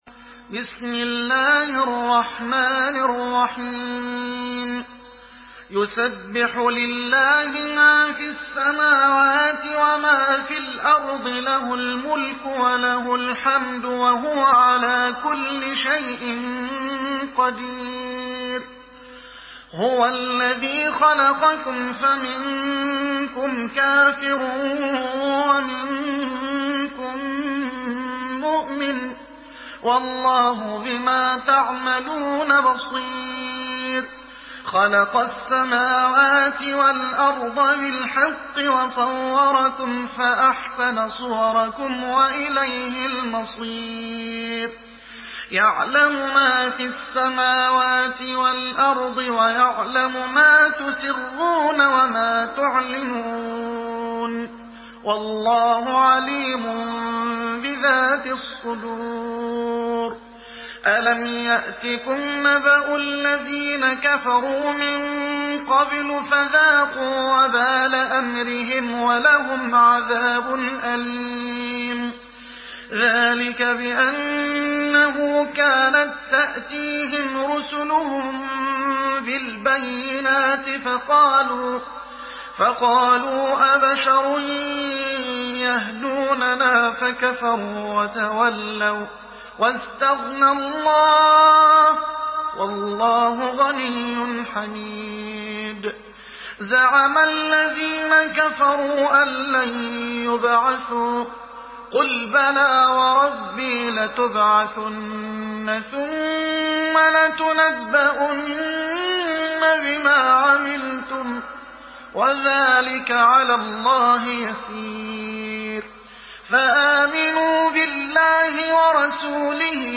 Surah التغابن MP3 by محمد حسان in حفص عن عاصم narration.
مرتل حفص عن عاصم